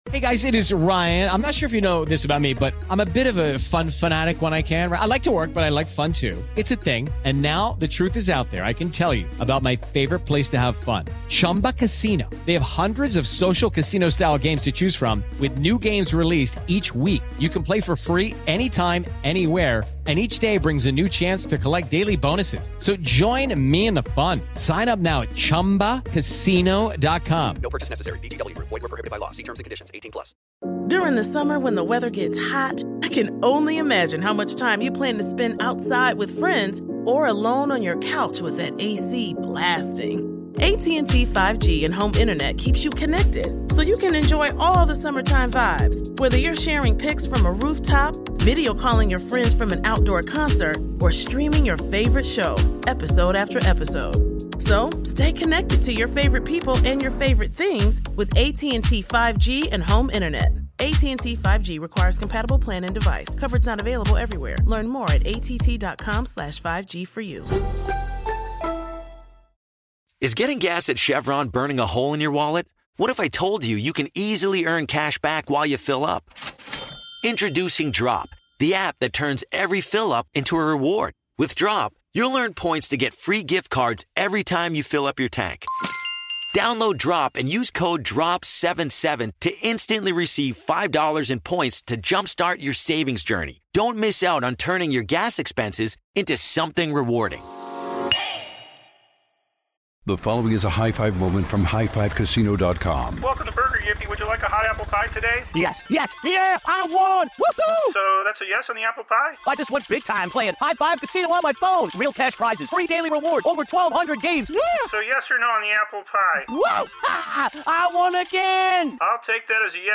The Veterans' History Project at the Atlanta History Center provides unedited first-person interviews from men and women who served our great country.